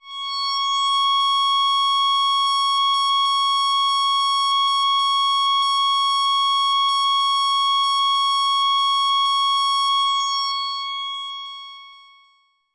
标签： CSharp6 MIDI音符-85 罗兰-JX-3P 合成器 单票据 多重采样
声道立体声